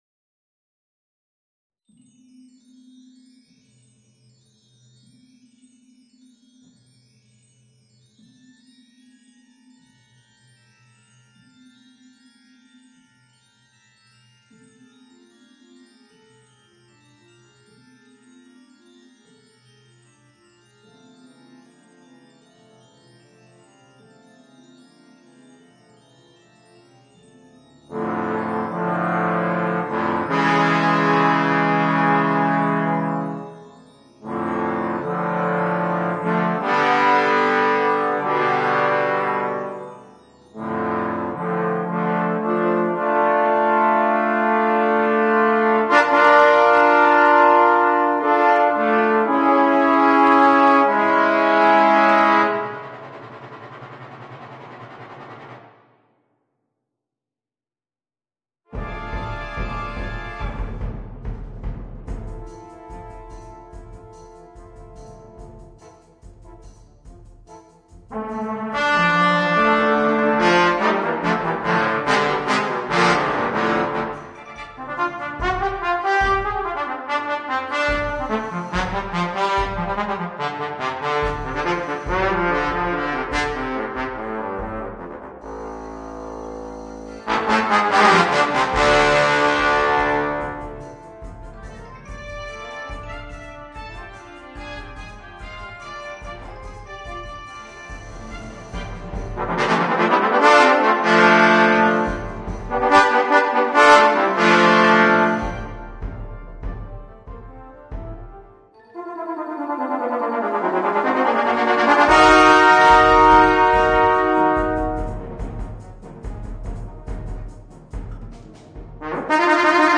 Voicing: 3 Trombones and Brass Band